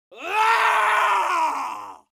crying-men-sound